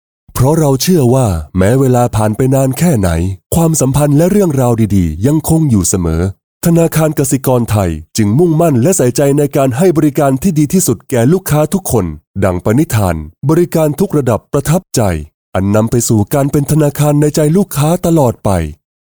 Voice Sample: Commercials Heartwarming
We use Neumann microphones, Apogee preamps and ProTools HD digital audio workstations for a warm, clean signal path.